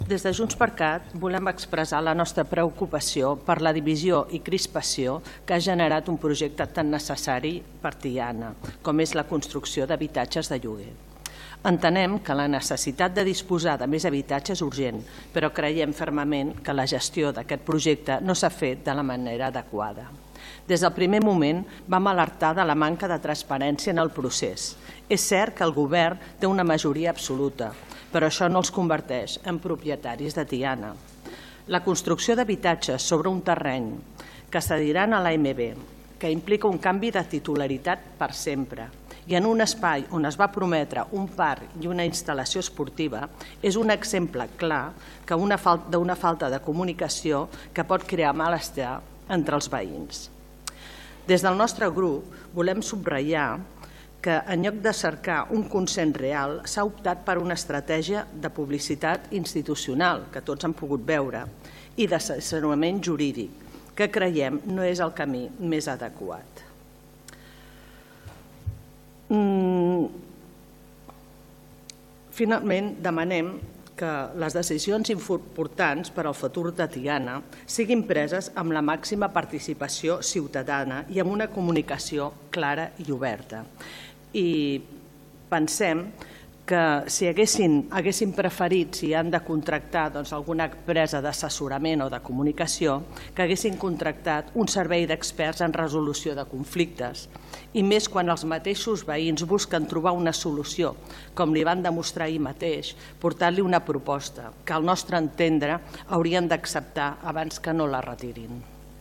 Per la seva part, la portaveu de Junts per Catalunya Tiana, Montse Torres, ha tornat a subratllar que la gestió del projecte s’ha fet de manera inadequada i ha demanat que el govern tingui en compte les propostes del veïnat de Can Gaietà: